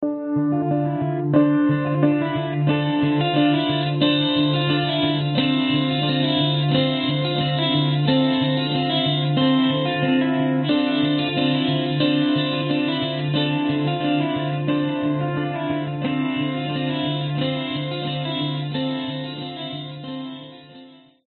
描述：原声吉他被处理成扫荡式的氛围。8条循环
标签： 慢节奏 吉他 循环 合成器 技术性音乐